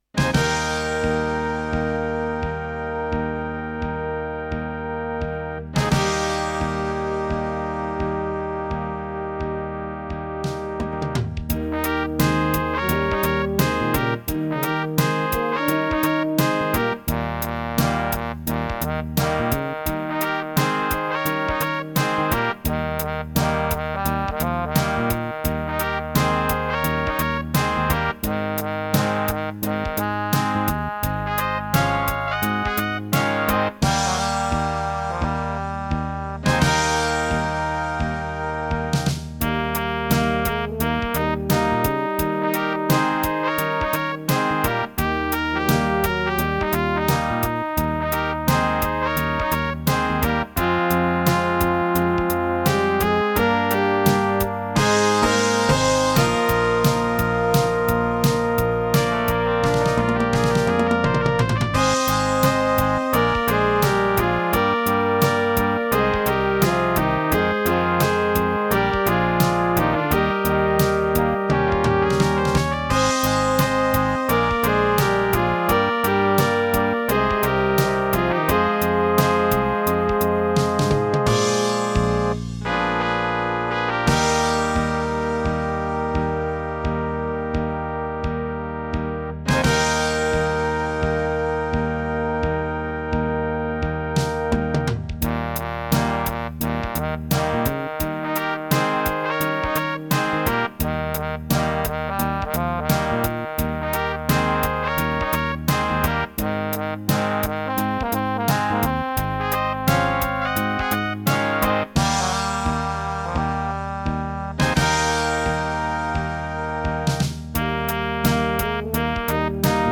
Gattung: Für kleine Besetzung
Besetzung: Kleine Blasmusik-Besetzung
Schlagzeug (ad libitum)
Akkordeon/Gitarre (ad libitum)